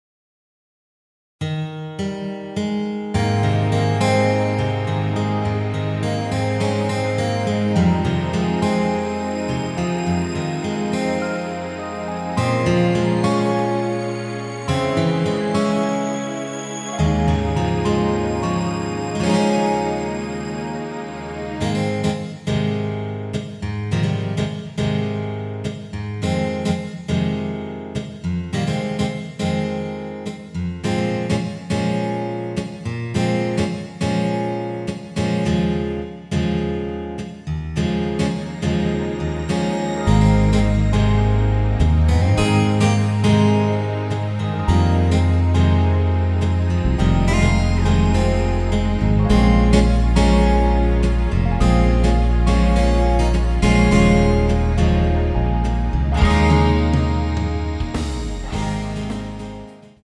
내린 MR입니다.
Ab
◈ 곡명 옆 (-1)은 반음 내림, (+1)은 반음 올림 입니다.
앞부분30초, 뒷부분30초씩 편집해서 올려 드리고 있습니다.